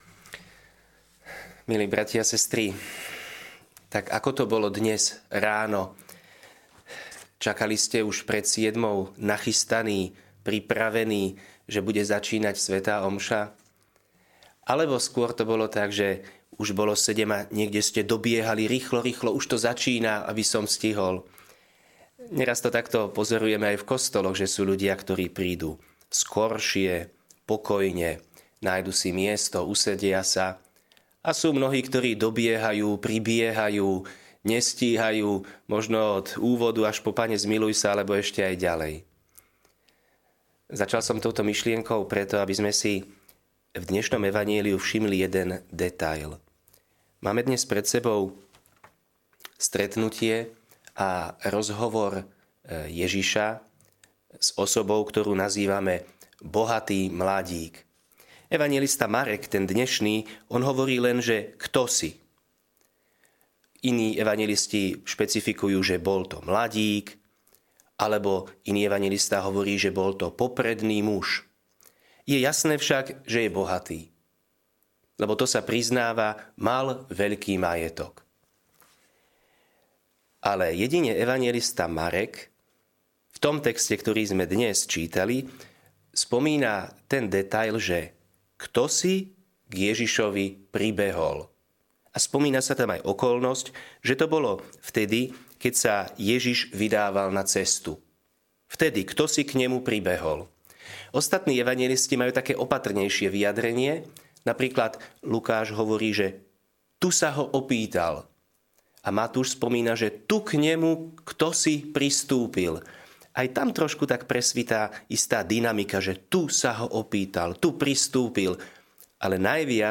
Podcasty Kázne S JEŽIŠOM SA TO NEDÁ VYBAVIŤ RÝCHLO A LACNO